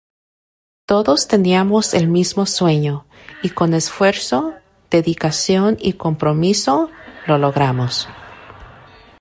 com‧pro‧mi‧so
Uitgesproken als (IPA)
/kompɾoˈmiso/